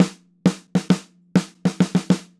Snare Drum Fill 04.wav